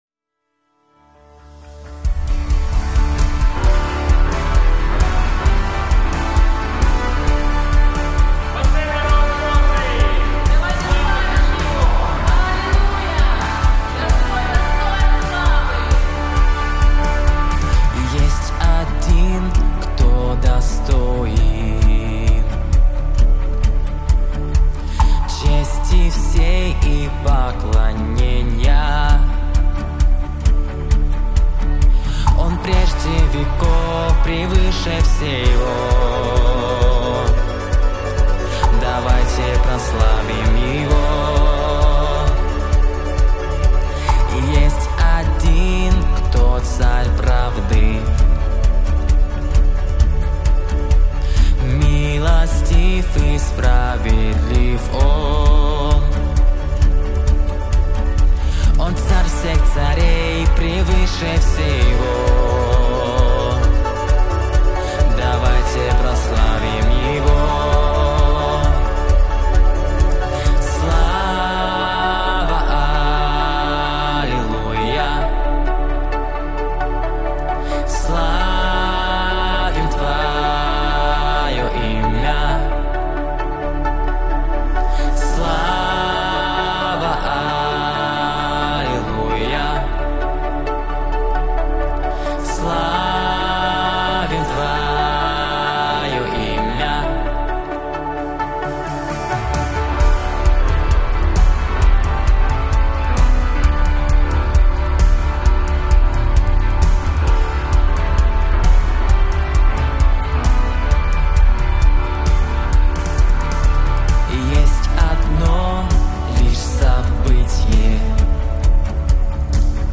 песня
127 просмотров 130 прослушиваний 18 скачиваний BPM: 132